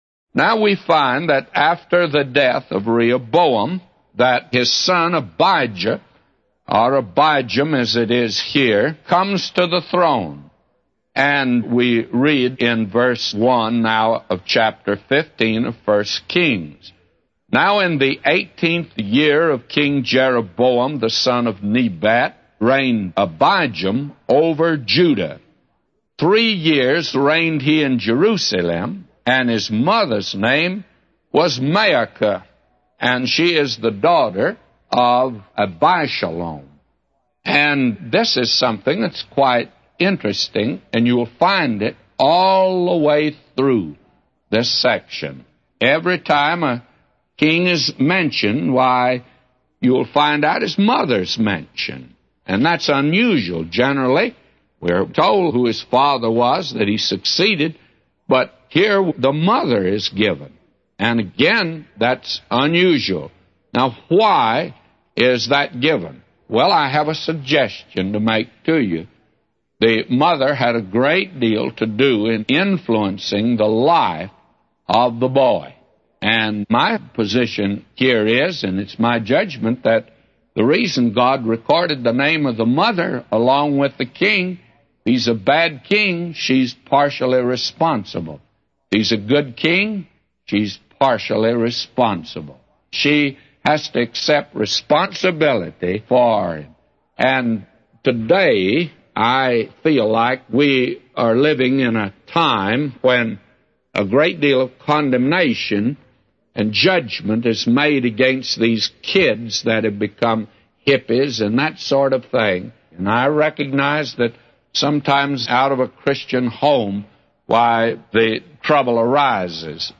A Commentary By J Vernon MCgee For 1 Kings 15:1-999